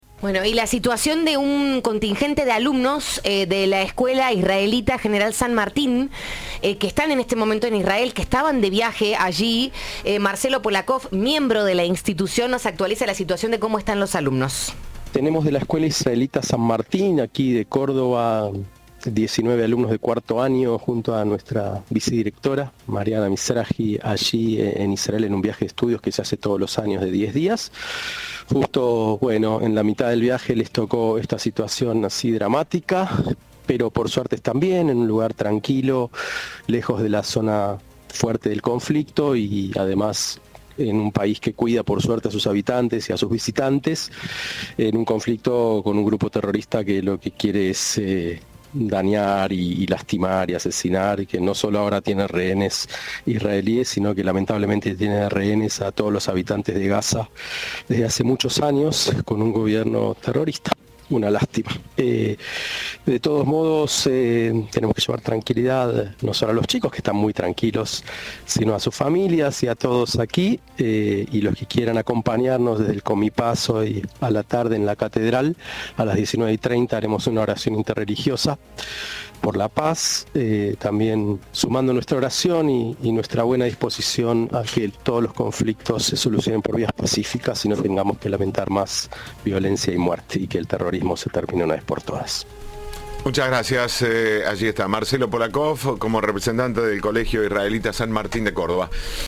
Entrevista de "Siempre Juntos".